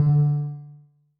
Techmino / media / effect / chiptune / ren_3.ogg
重做连击音效